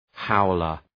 Προφορά
{‘haʋlər}